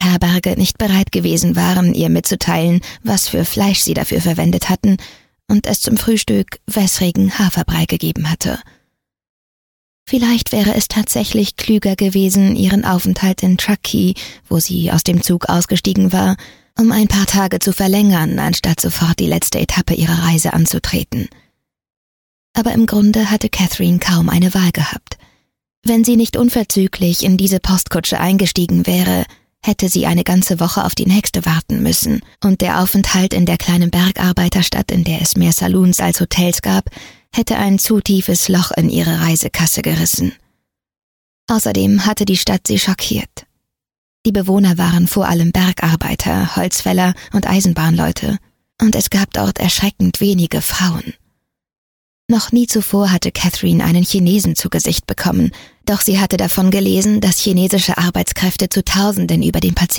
Mein wildes, mutiges Herz - Hörbuch
Die Varianz ihrer Stimme verleiht den unterschiedlichen Figuren der Geschichte einen ganz eigenen Charakter, sodass man ihr im wahrsten Sinne des Wortes stundenlang gerne zuhört.